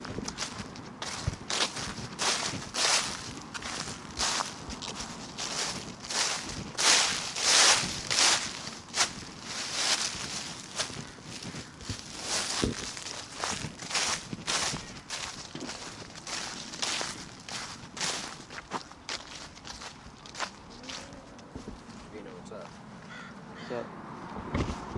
描述：嘎吱嘎吱的一片叶子。
Tag: 叶紧缩 WAV 秋天 叶子 死叶